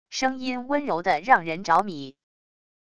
声音温柔的让人着迷wav音频